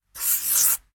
Dibujar un círculo
Sonidos: Acciones humanas
Sonidos: Oficina